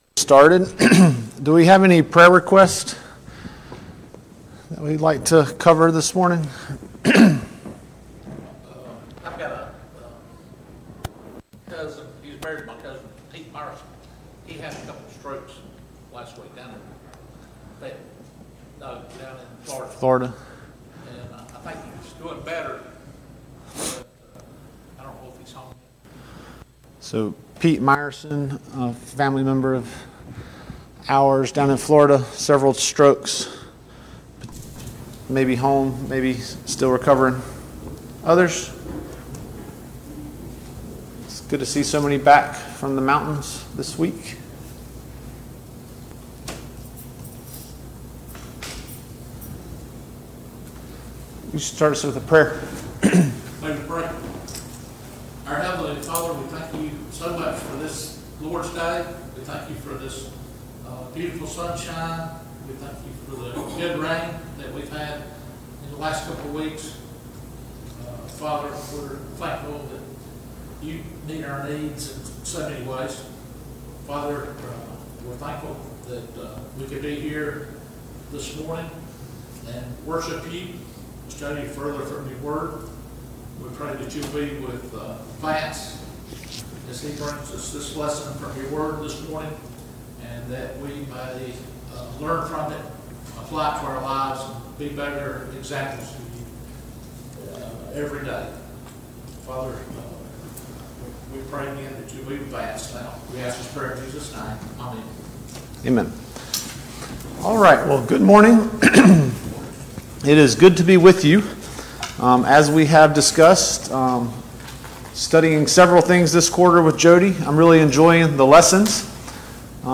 Sunday Morning Bible Class « The Providence of God Can we watch and pray with Him for one hour?